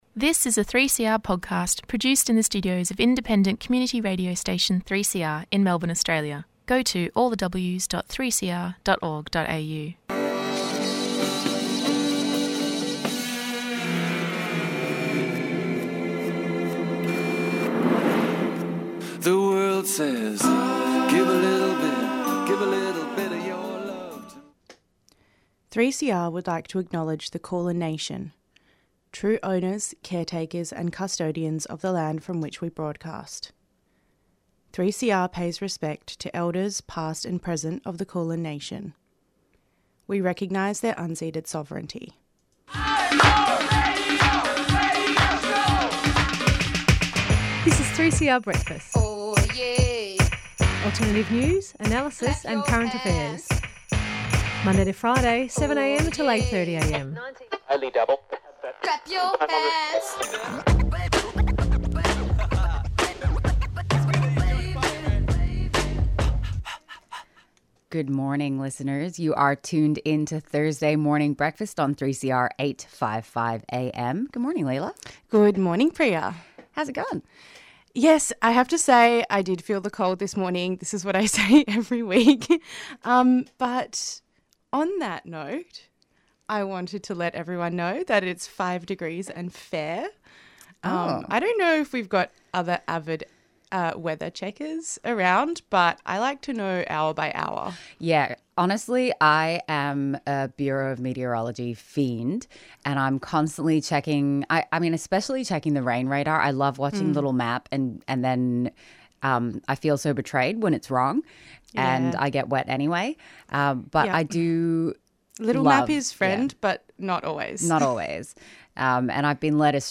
Acknowledgement of Country// Headlines//
This interview also included some tips on how to keep yourself safer at events and actions, an important listen in an era of escalating heightened police surveillance and violence against autonomous activists.